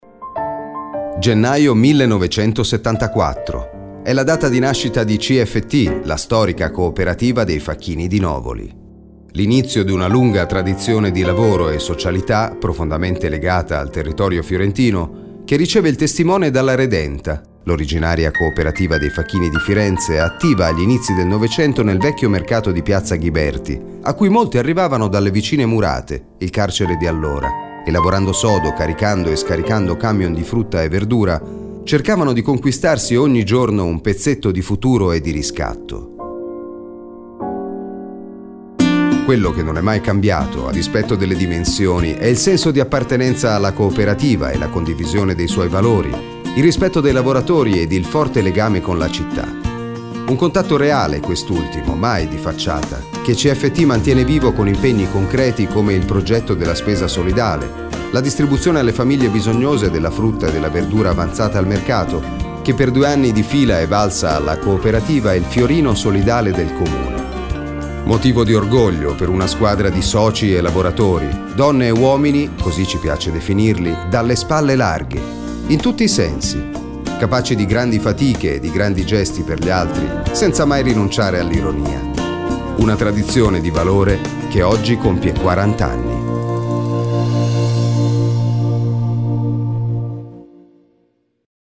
İtalyanca Seslendirme